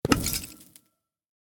eject1.ogg